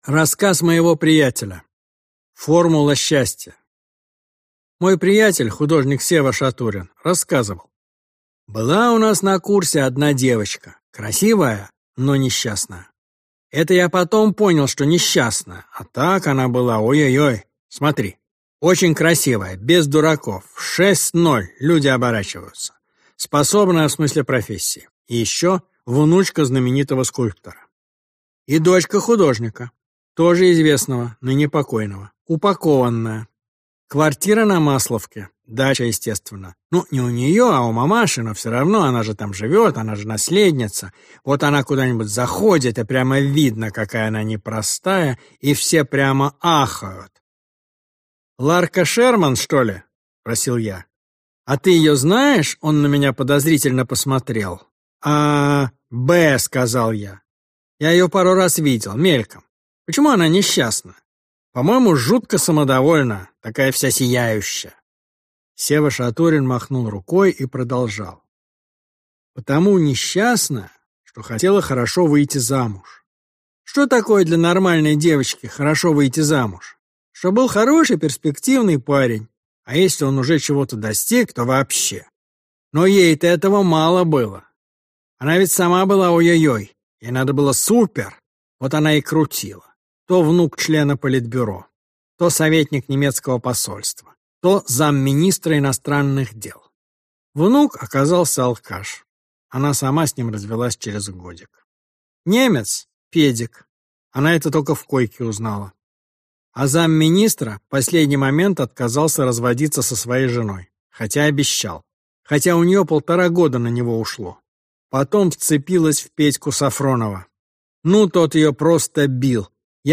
Аудиокнига Советский секс. Теория и практика | Библиотека аудиокниг
Теория и практика Автор Денис Драгунский Читает аудиокнигу Денис Драгунский.